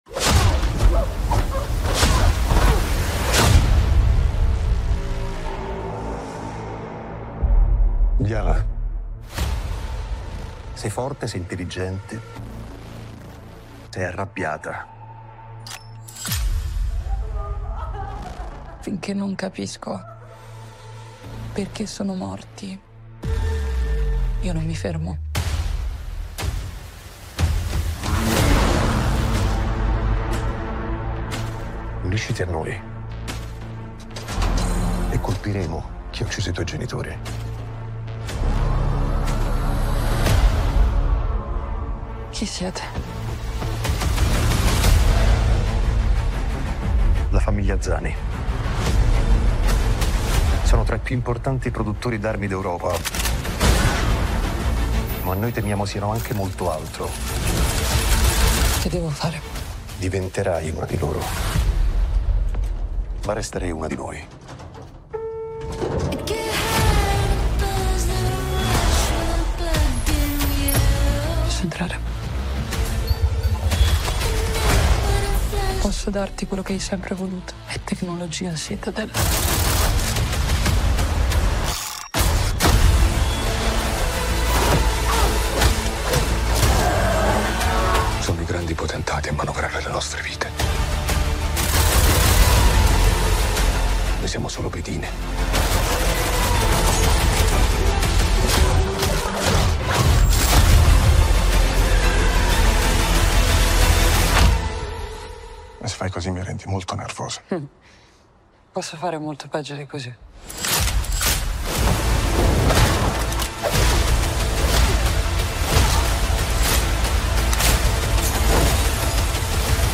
Citadel: Diana - Trailer Ufficiale - Prime Video